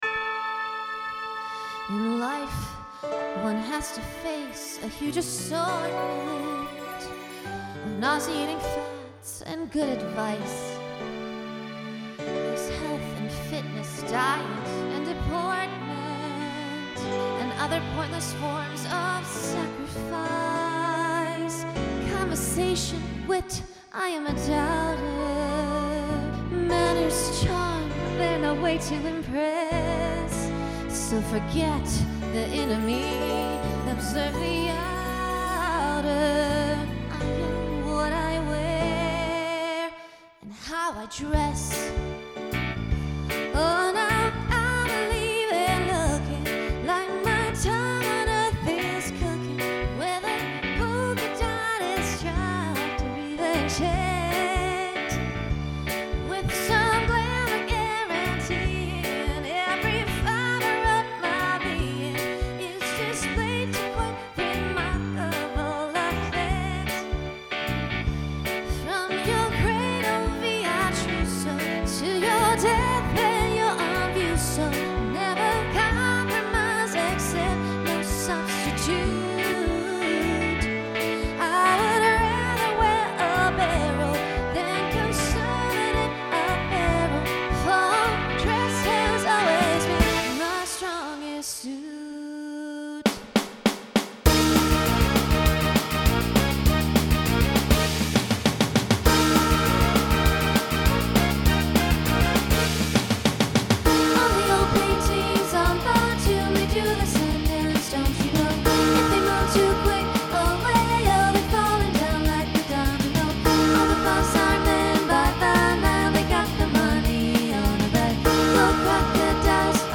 Voicing SSA Instrumental combo Genre Broadway/Film , Rock